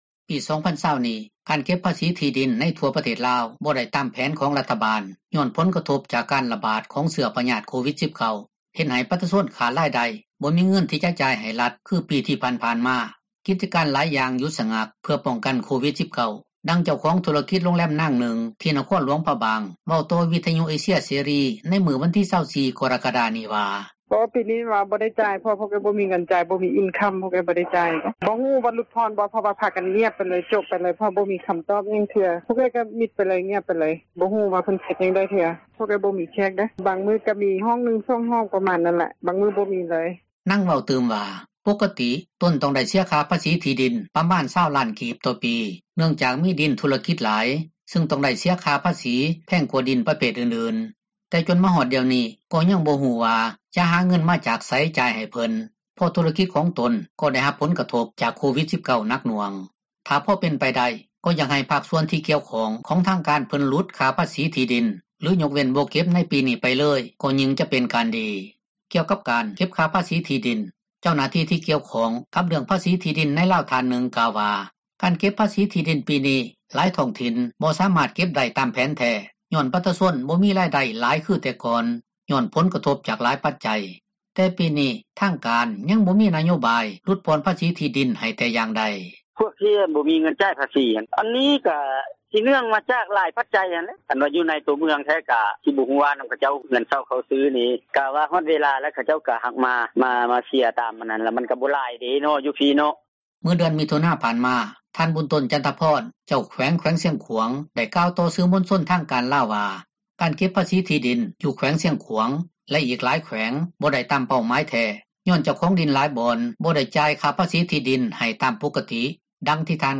ປີ 2020 ນີ້ ການເກັບພາສີທີ່ດິນ ໃນທົ່ວປະເທດລາວ ບໍ່ໄດ້ຕາມແຜນຂອງຣັຖບານ ຍ້ອນຜົລກະທົບ ຈາກ ການຣະບາດຂອງເຊື້ອພຍາດ ໂຄວິດ-19 ເຮັດໃຫ້ປະຊາຊົນ ຂາດຣາຍໄດ້ ບໍ່ມີເງິນທີ່ຈະໃຫ້ຣັຖ ຄືປີທີ່ຜ່ານໆມາ, ກິຈການຫລາຍຢ່າງຢຸດຊະງັກ ເພື່ອປ້ອງກັນໂຄວິດ-19, ດັ່ງເຈົ້າຂອງທຸຣະກິດໂຮງແຮມນາງນຶ່ງ ທີ່ ນະຄອນຫຼວງພຣະບາງ ເວົ້າຕໍ່ວິທຍຸເອເຊັຽເສຣີ ໃນມື້ວັນທີ 24 ກໍຣະກະດາ ນີ້ວ່າ: